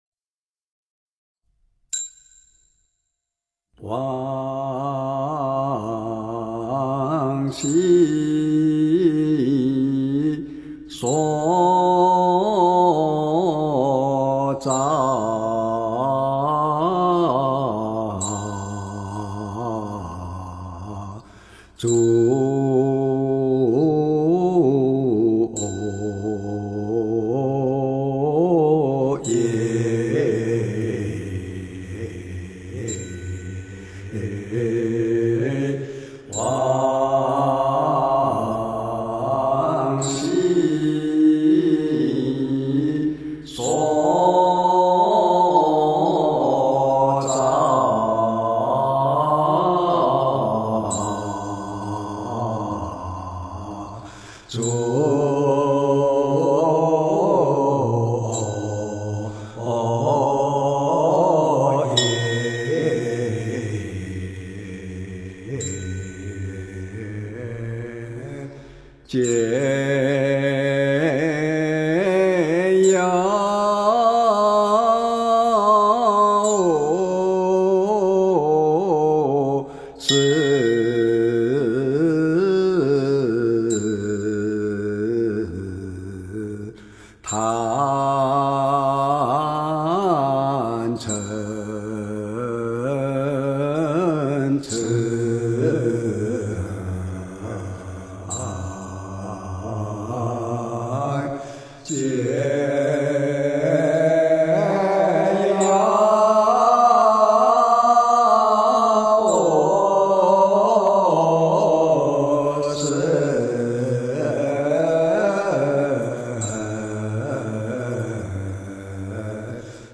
佛音 诵经 佛教音乐 返回列表 上一篇： 拜愿 下一篇： 观音圣号(闽南语合唱版